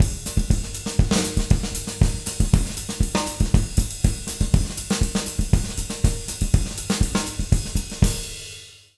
240SAMBA01-L.wav